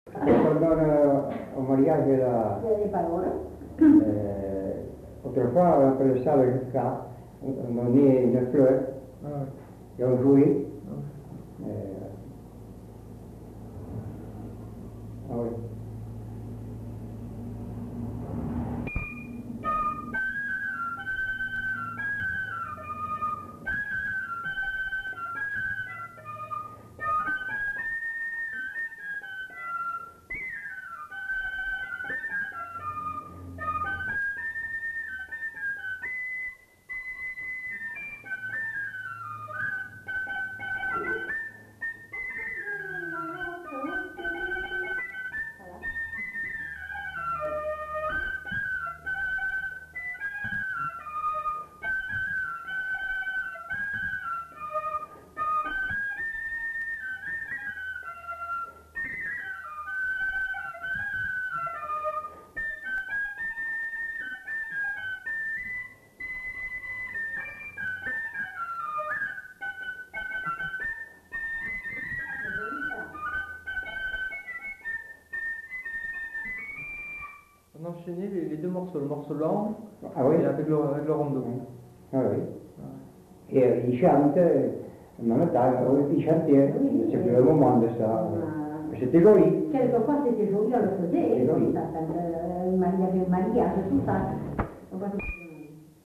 Lieu : Bazas
Genre : morceau instrumental
Instrument de musique : fifre
Notes consultables : Enchaînement d'un air lent avec un rondeau.